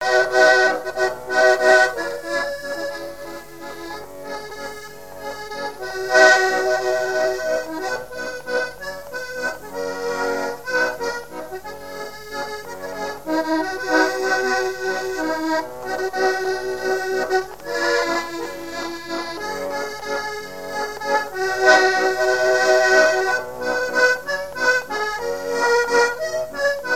danse : valse
Genre strophique
Pièce musicale inédite